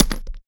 grenade_hit_carpet_03.WAV